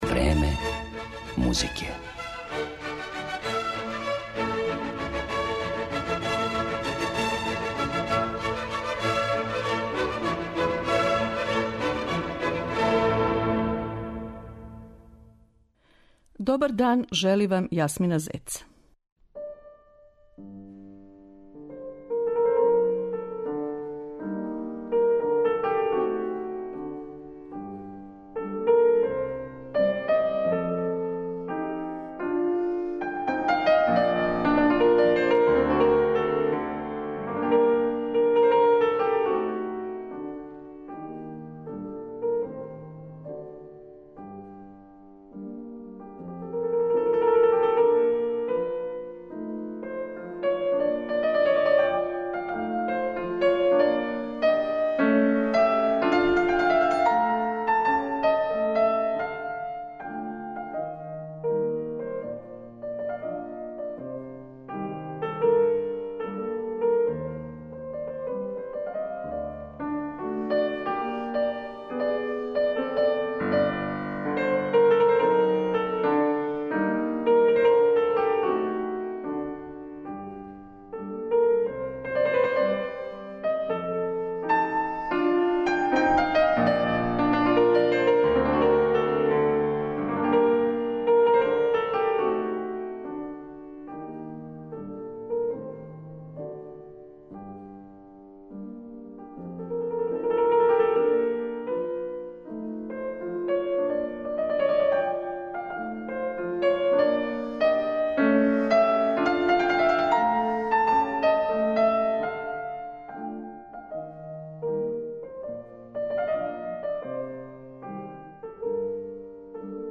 Данас, на таласима Радио Београда 2, представићемо ову врхунску уметницу снимцима Шопенових, Шубертових и Моцартових дела.